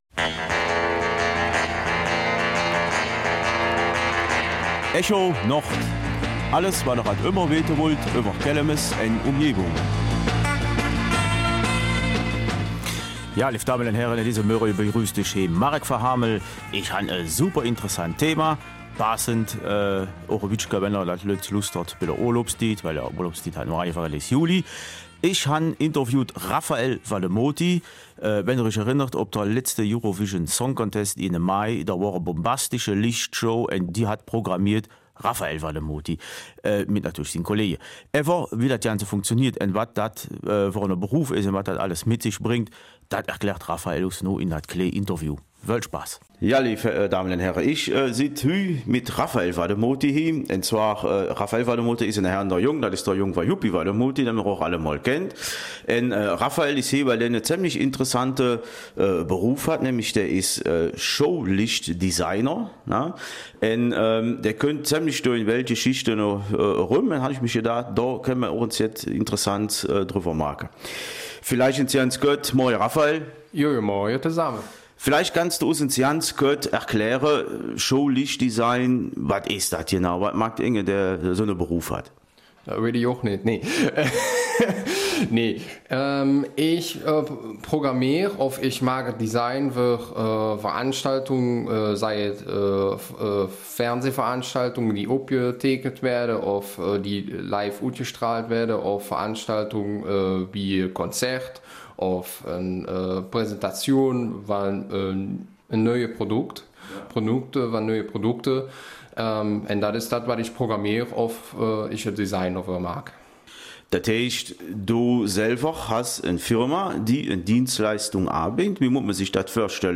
Kelmiser Mundart: Der Beruf des Lichtdesigners